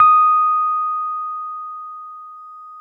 RHODES CL0JL.wav